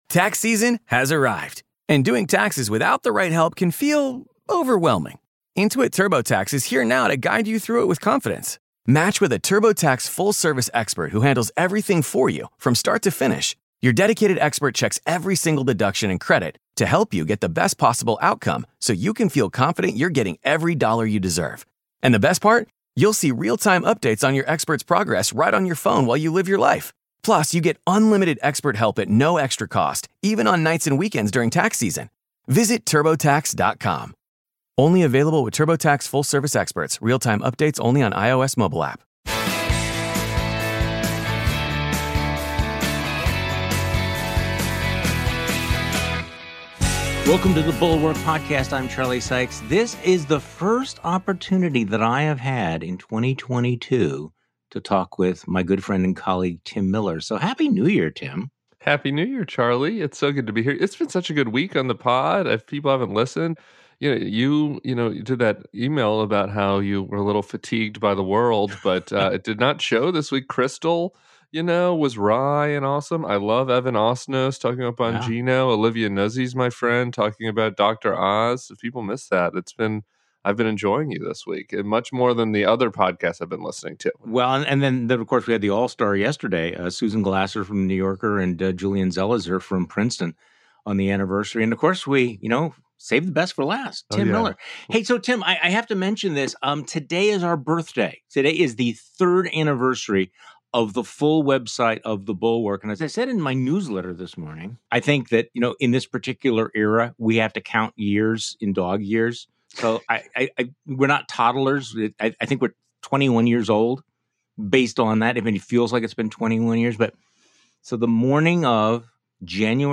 Ted Cruz found out what happens when you don't use the language of the MAGA woke orthodoxy. Plus, liberals grousing about Dick Cheney: Do you want a pro- democracy coalition or don’t you? Tim Miller joins Charlie Sykes on today's podcast.
Special Guest: Tim Miller.